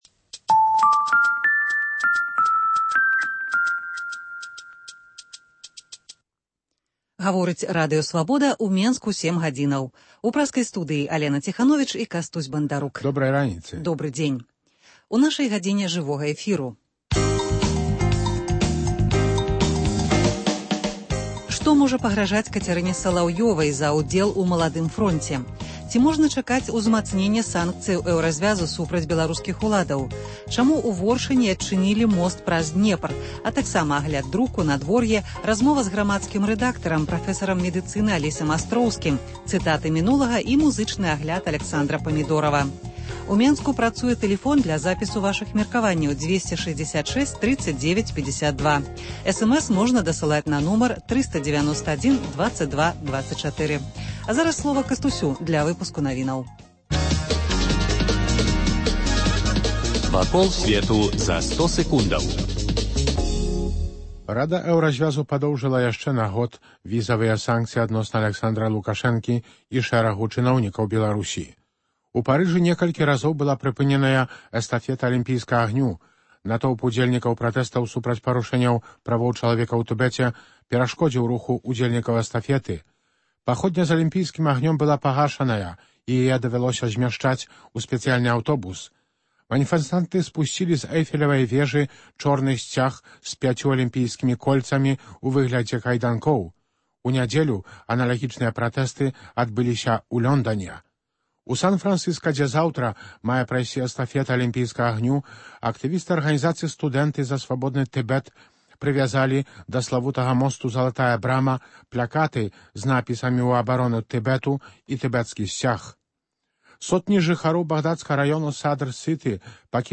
Навіны Беларусі й сьвету, надвор'е, агляд друку, гутарка з госьцем, ранішнія рэпартажы, бліц-аналіз, музычная старонка.
* У жывым эфіры – гутарка зь нядаўна вызваленымі, што адбывалі пакараньне за ўдзел у акцыях на Дзень Волі .